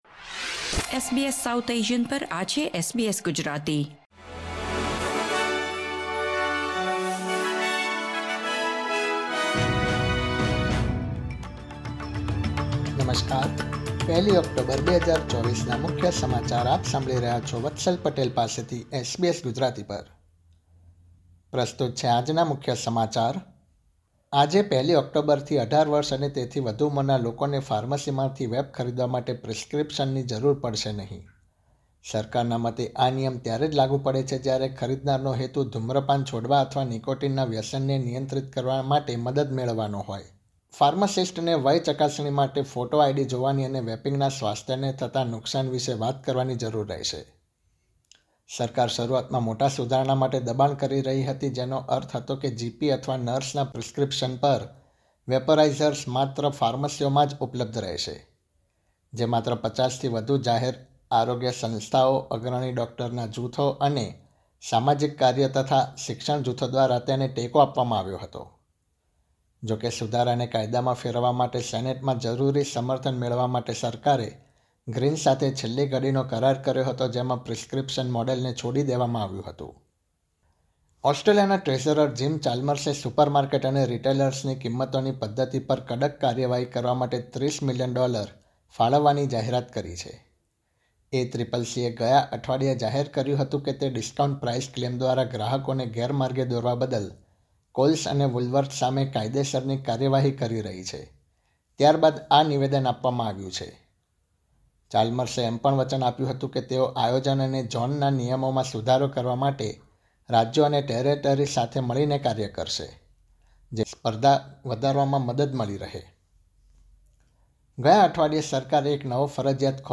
SBS Gujarati News Bulletin 1 October 2024